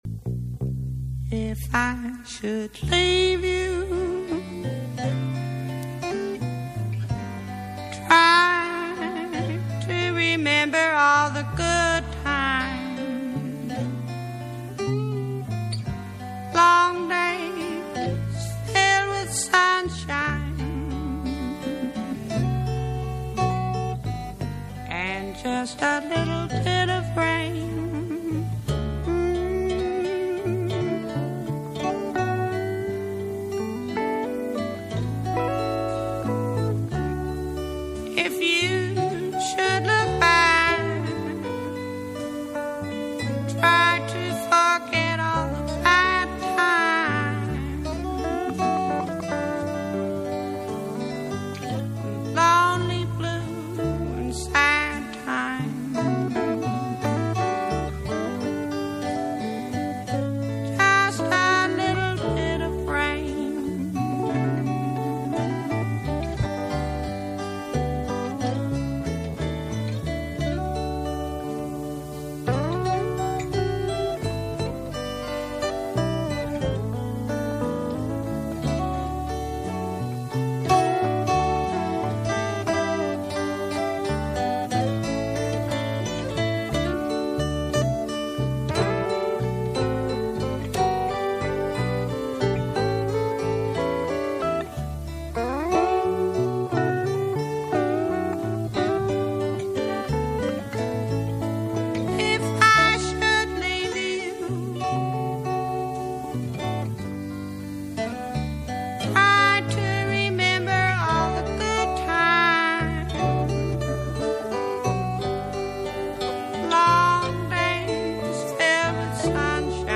Recorded to CDR at the free103point9 Project Space and Gallery.